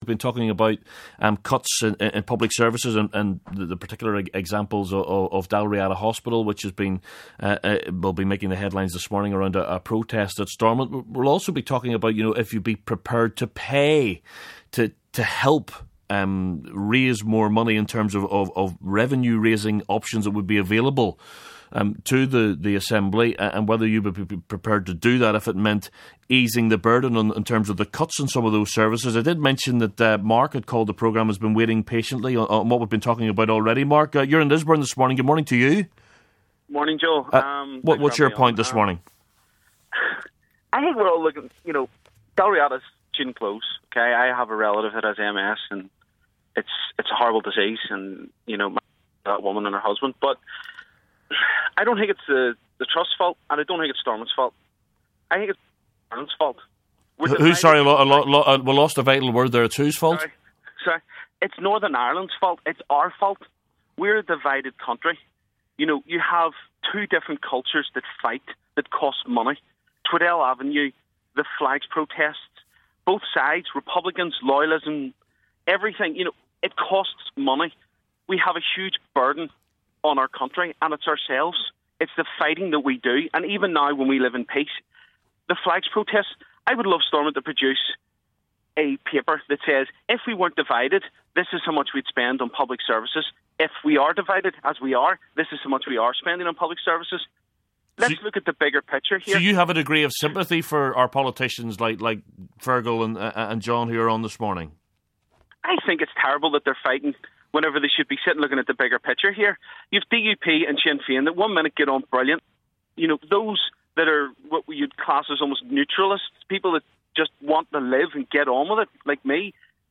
Part 2 - SF's John O'Dowd and SDLP's Fearghal McKinney clash over Dalriada hospital closure